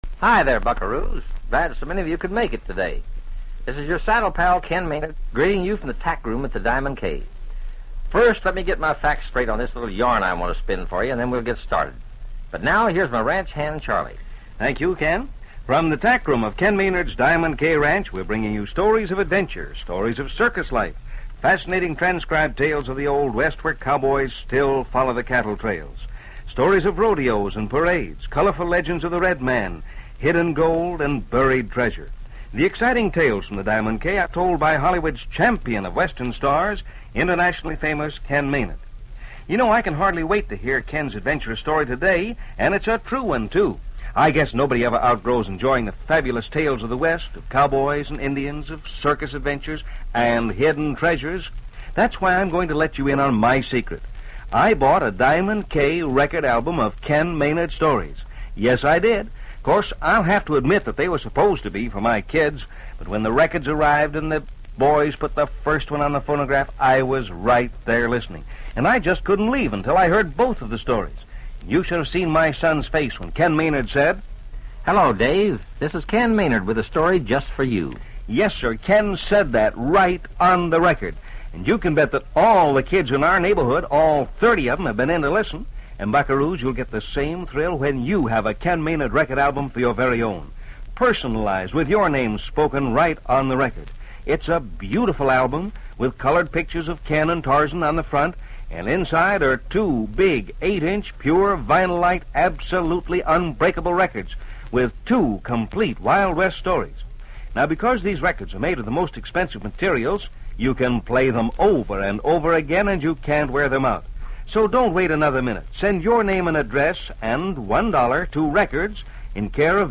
"Tales from the Diamond K" was a syndicated radio show aimed at a juvenile audience, broadcasted during the mid-1950s. - The show featured a variety of stories, mostly set in the Old West, and was designed to entertain and educate its young listeners. - Ken Maynard, a pioneer singing cowboy and film star, hosted the show, introducing a different story each day.